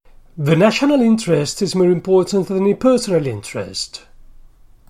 In none of the above sentences, the last accent is placed on the last lexical word but on the immediately previous one.